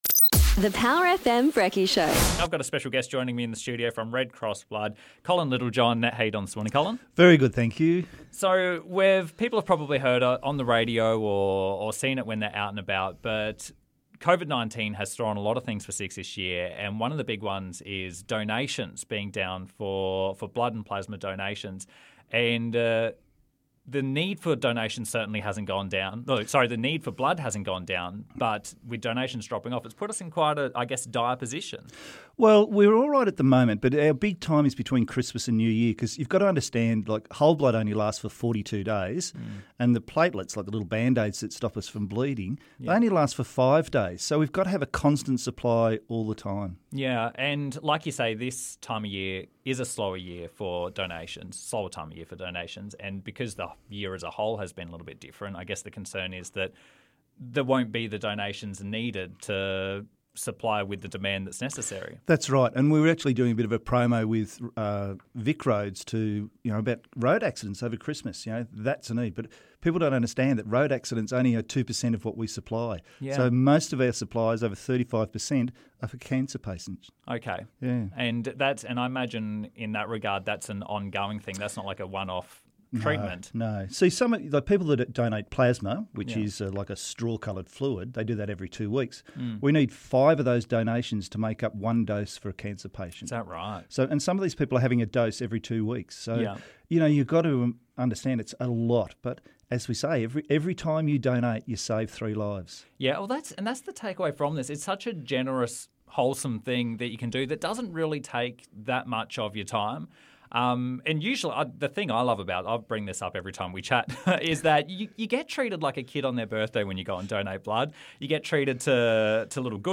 Lifeblood Interview